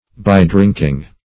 By-drinking \By"-drink`ing\, n. A drinking between meals.